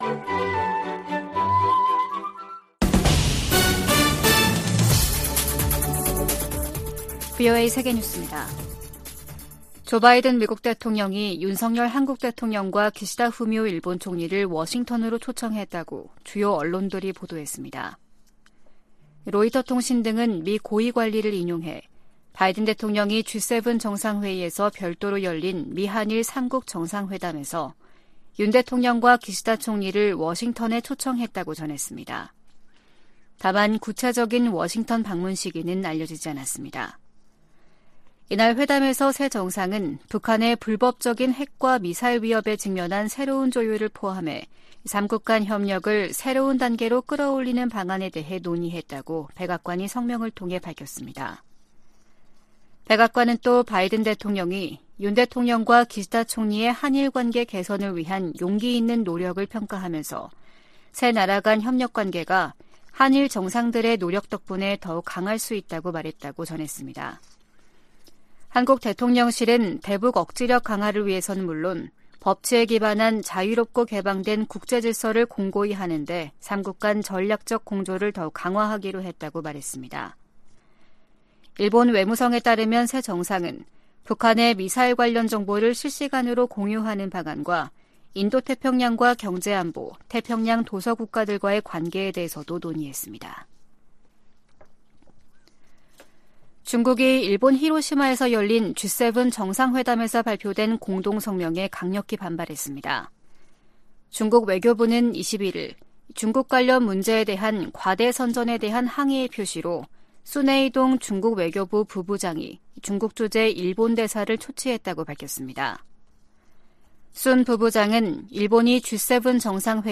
VOA 한국어 아침 뉴스 프로그램 '워싱턴 뉴스 광장' 2023년 5월 23일 방송입니다. 조 바이든 미국 대통령과 윤석열 한국 대통령, 기시다 후미오 일본 총리가 히로시마 회담에서 새로운 공조에 합의했습니다. 주요7개국(G7) 정상들은 히로시마 공동성명에서 북한의 계속되는 핵과 탄도미사일 위협을 규탄했습니다. 미일 외교장관들이 북한의 완전한 비핵화를 위한 미한일 3각 공조의 중요성을 강조했습니다.